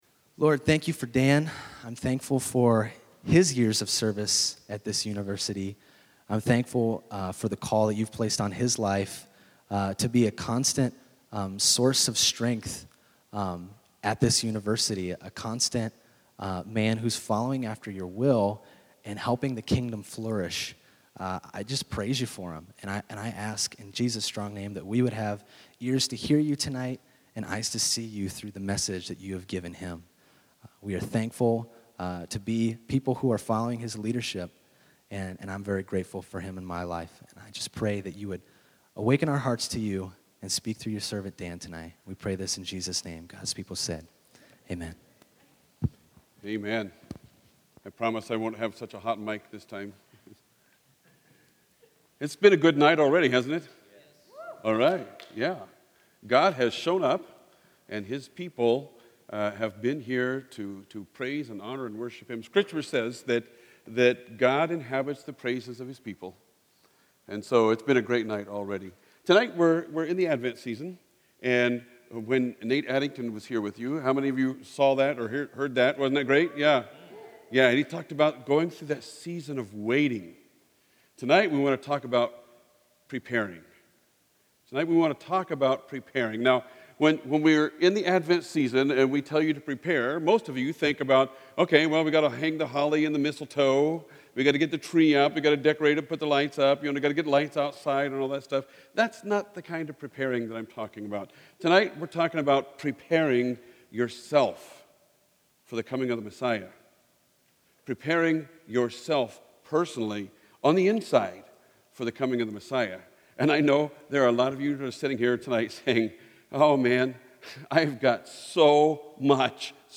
Sermons - The Well